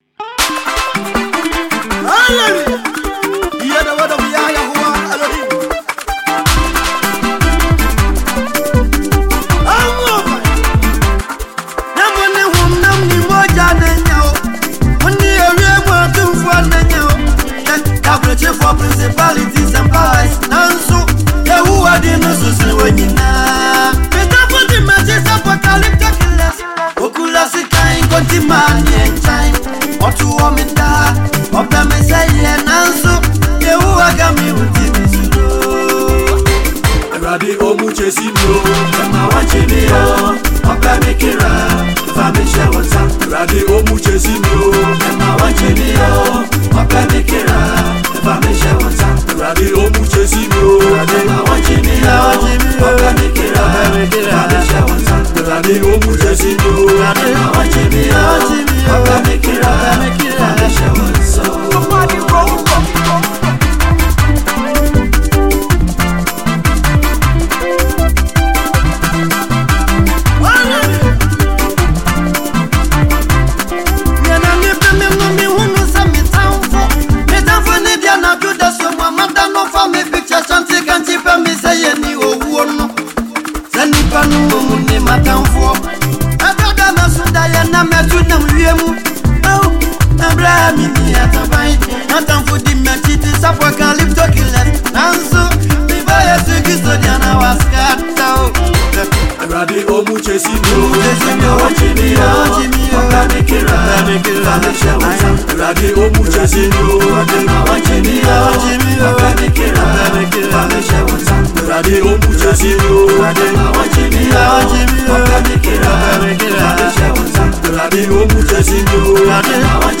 Ghanaian gospel musician